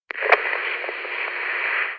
walkietalkie.mp3